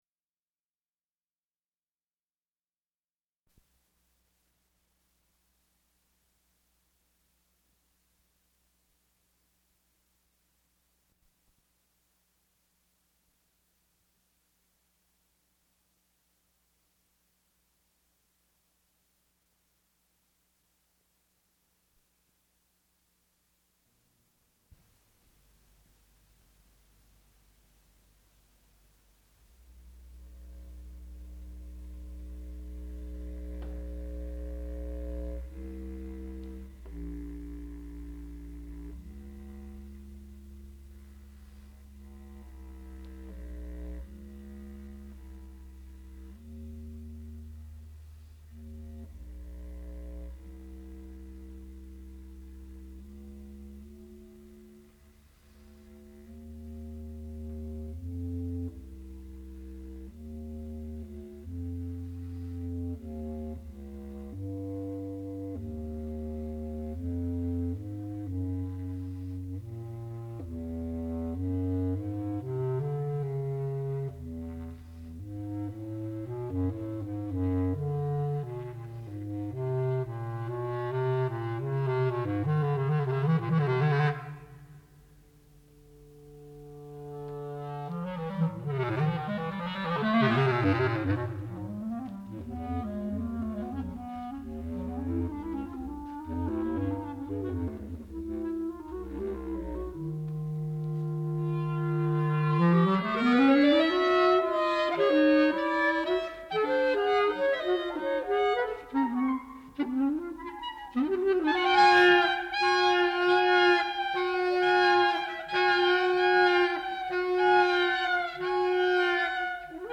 Response I (1968), for two percussion groups and magnetic tape
sound recording-musical
classical music
percussion